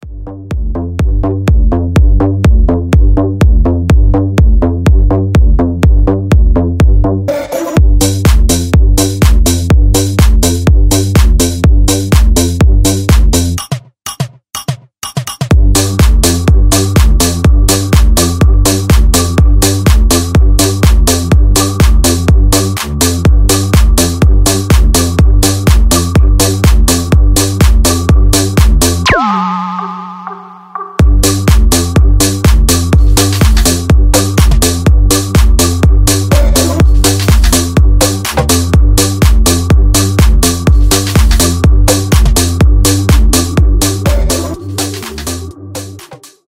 без слов
клубные , tech house , edm , энергичные